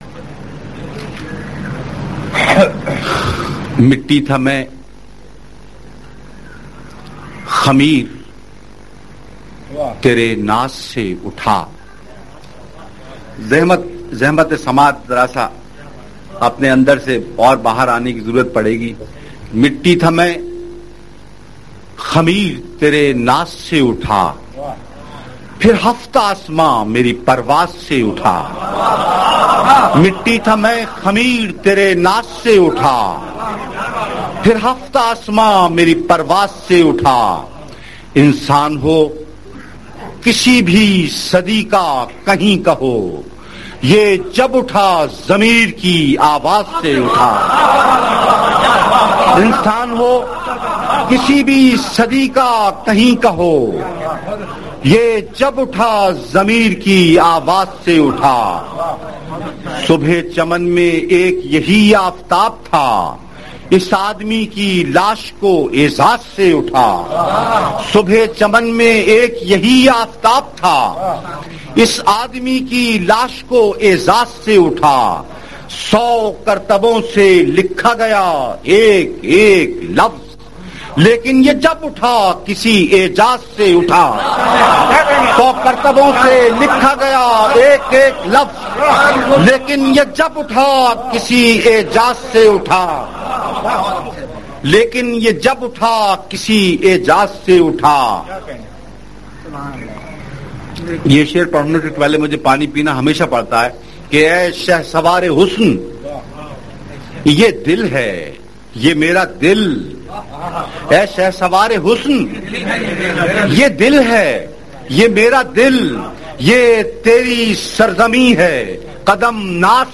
منظوم کلام نظمیں (Urdu Poems)
Voice: Obaidullah Aleem